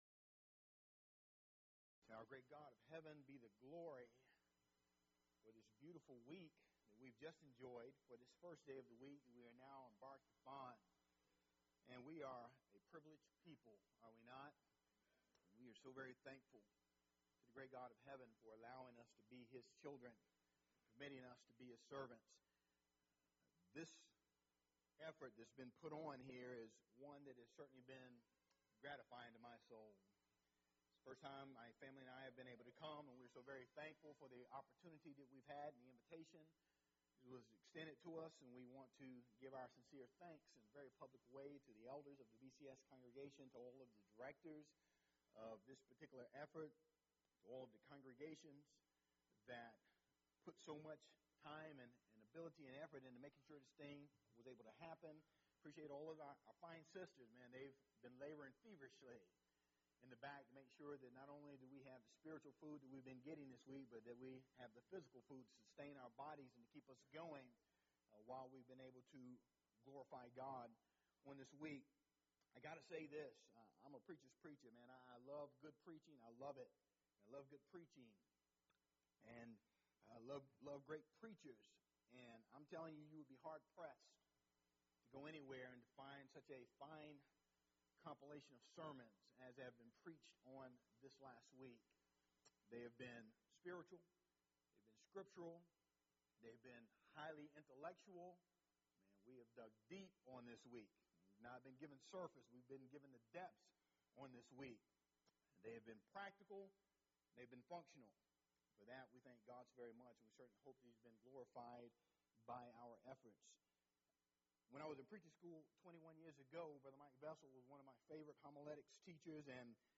Event: 4th Annual Men's Development Conference
lecture